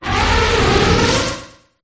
copperajah_ambient.ogg